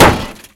Index of /server/sound/vcmod/collision/light